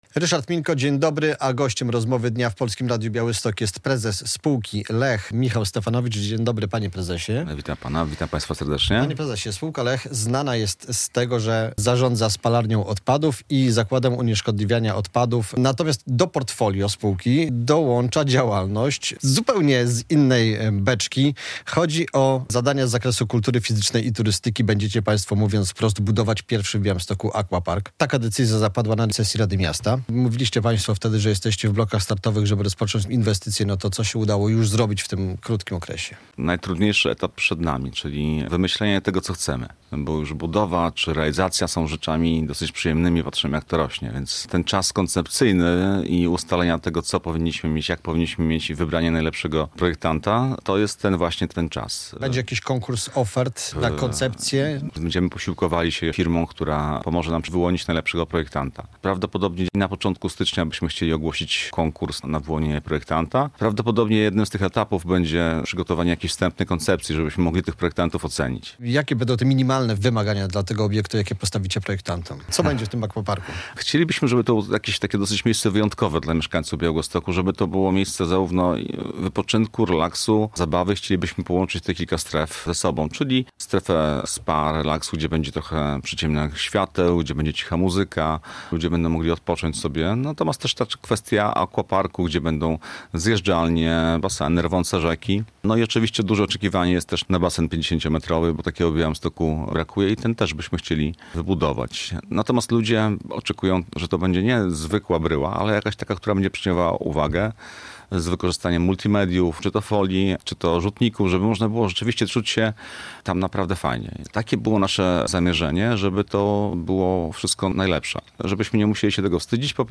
Radio Białystok | Gość